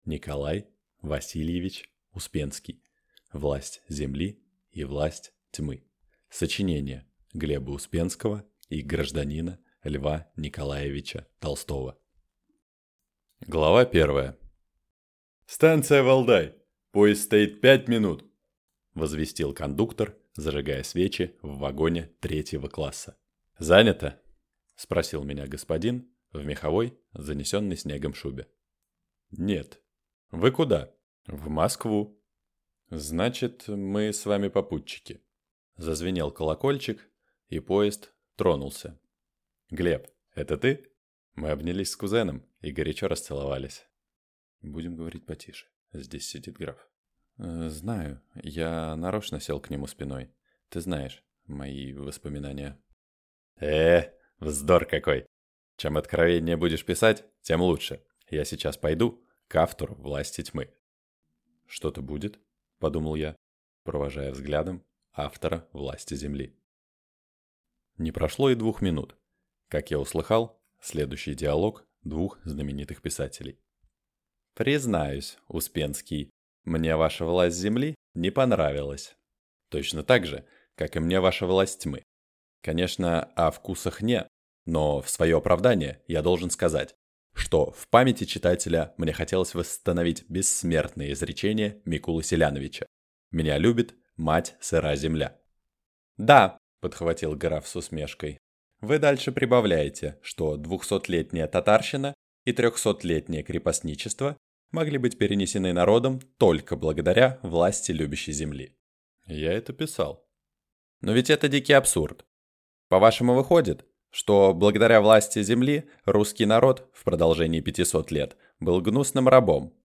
Аудиокнига «Власть земли» и «Власть тьмы» (соч. Гл. Успенского и гр. Л.Н. Толстого) | Библиотека аудиокниг